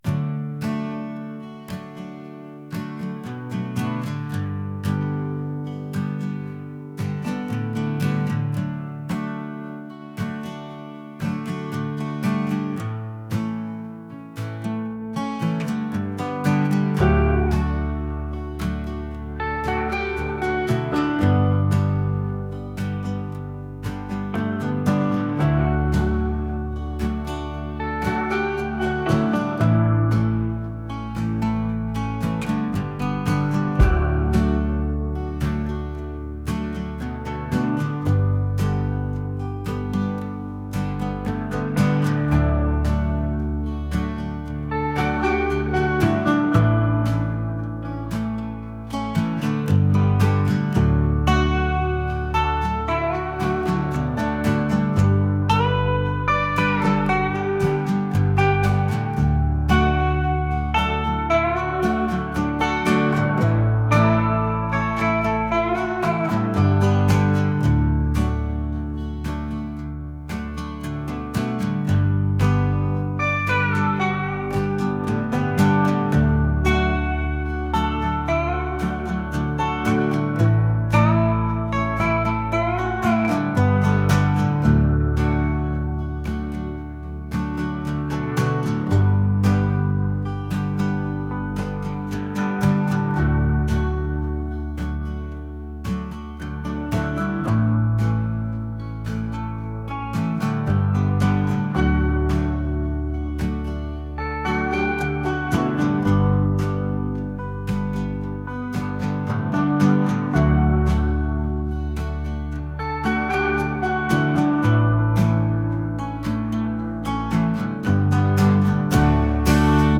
acoustic | folk | indie